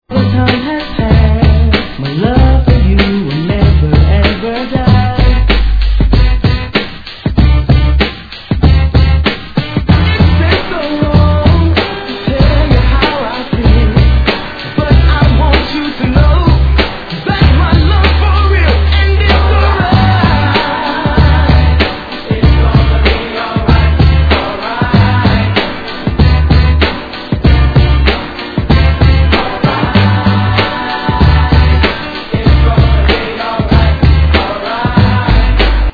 Tag       NEW JACK R&B